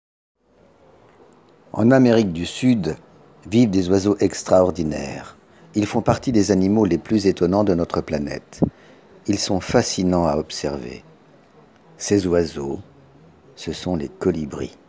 Voix off
55 - 100 ans - Mezzo-soprano